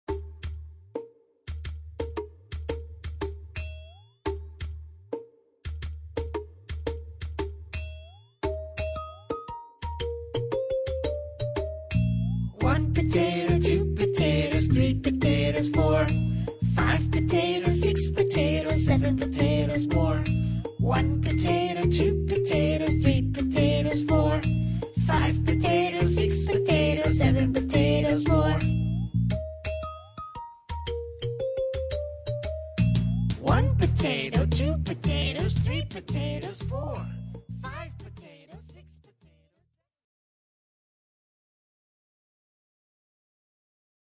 In P3, we are learning a counting rhythm!